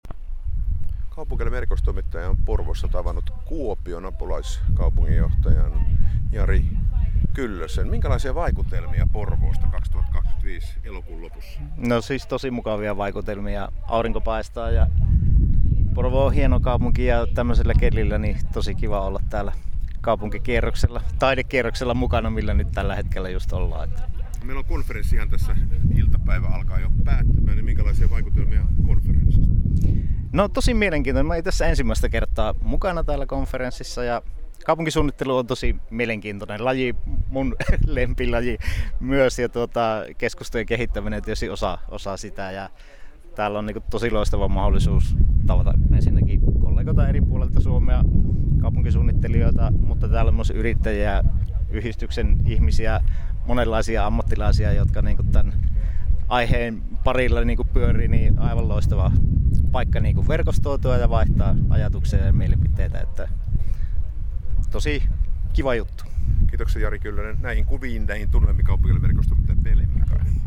Kuopion apulaiskaupunginjohtaja Jari Kyllönen osallistui Citykonferenssin kakkospäivänä yhteiselle taidekävelylle.